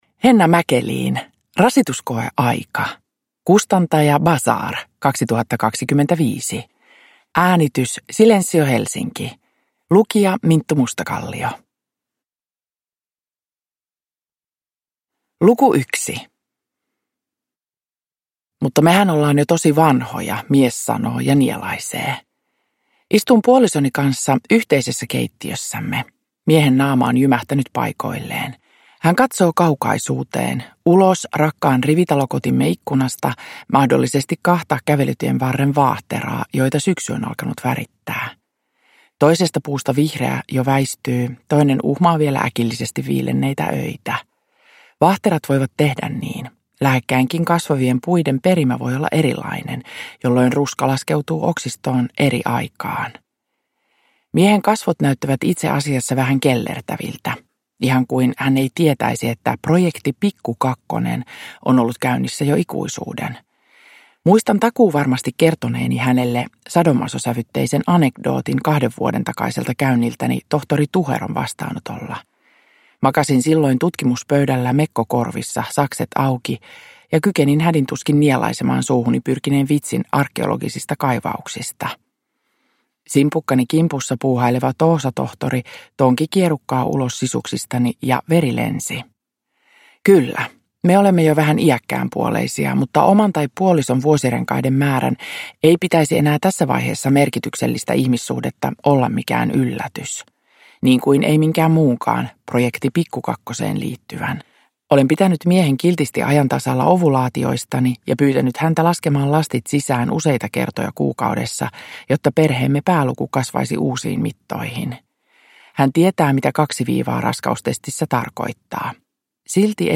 Rasituskoeaika – Ljudbok